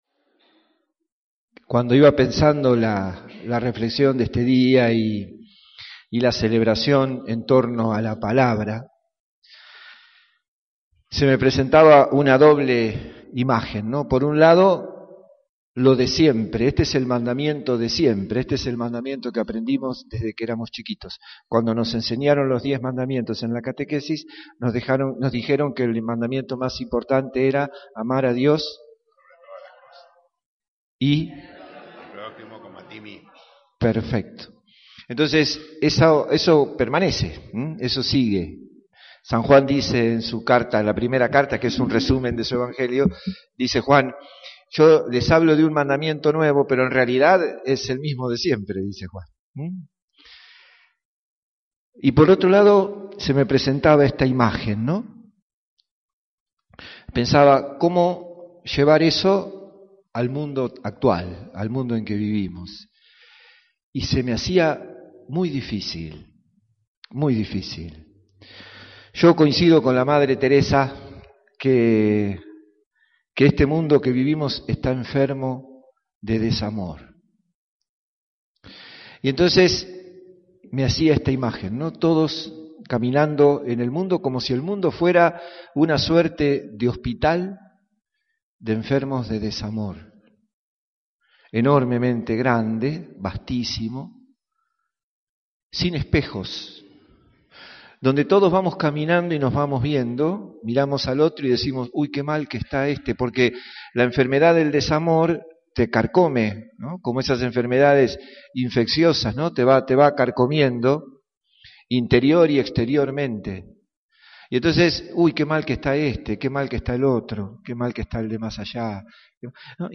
INTRODUCCIÓN A LA MISA